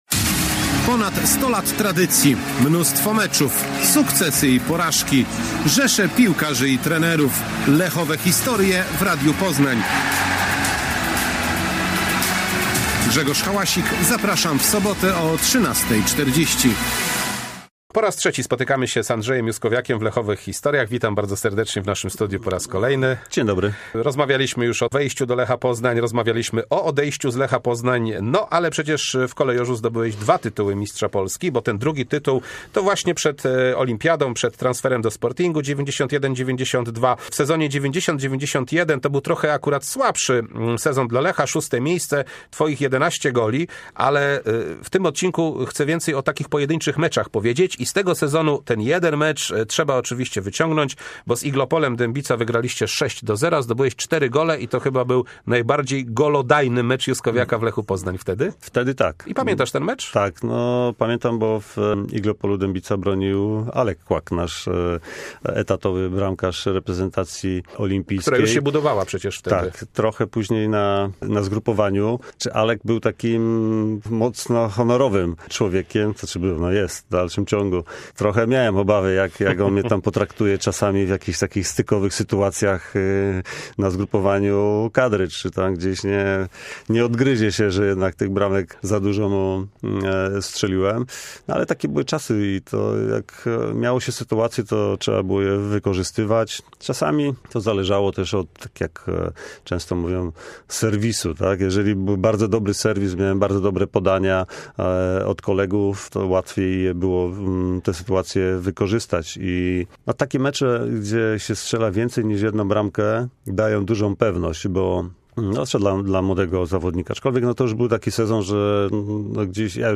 40-ty odcinek Lechowych historii to trzecia, ostatnia część rozmowy z Andrzejem Juskowiakiem.